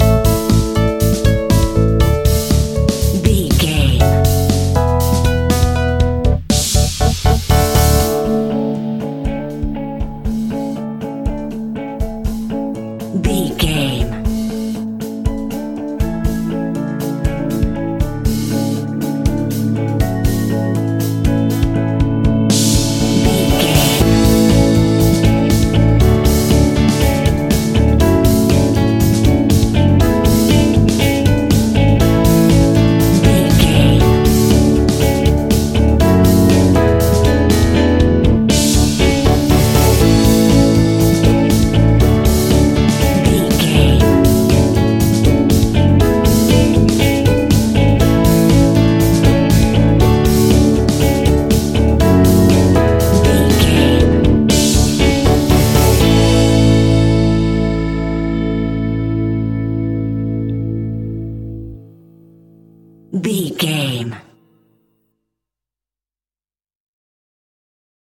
Ionian/Major
indie pop
fun
energetic
uplifting
upbeat
groovy
guitars
bass
drums
piano
organ